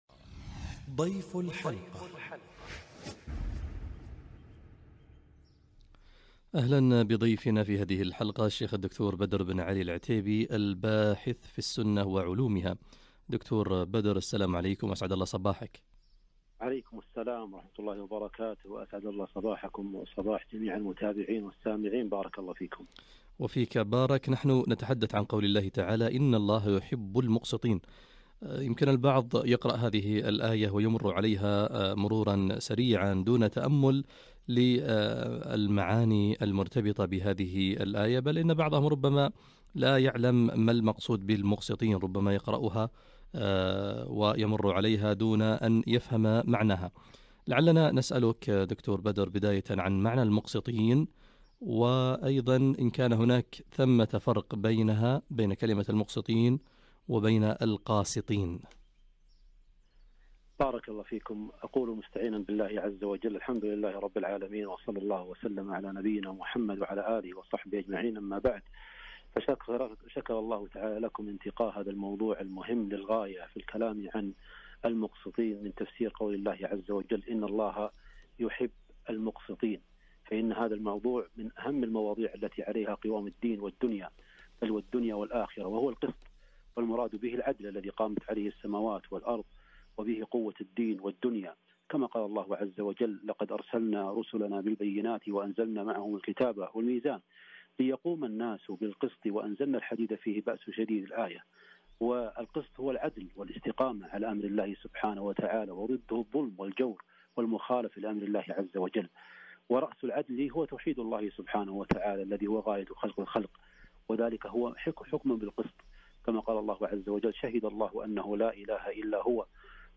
{ إن الله يحب المقسطين } - لقاء إذاعي